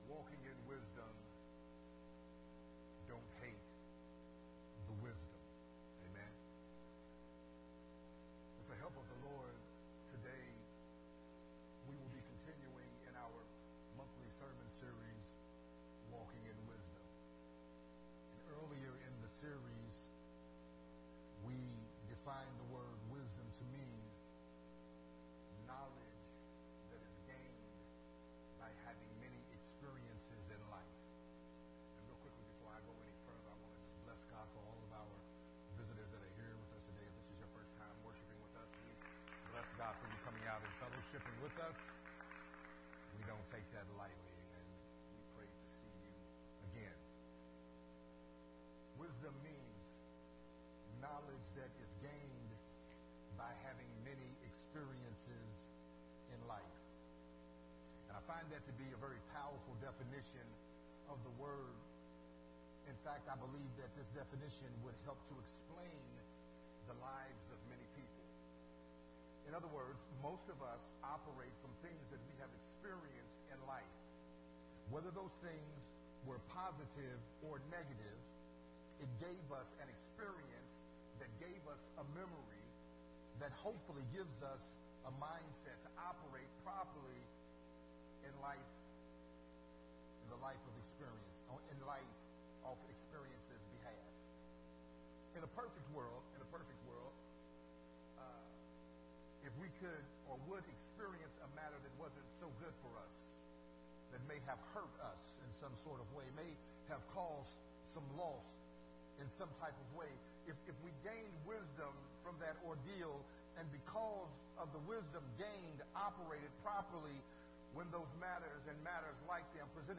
Part 3 of the sermon series
recorded at Unity Worship Center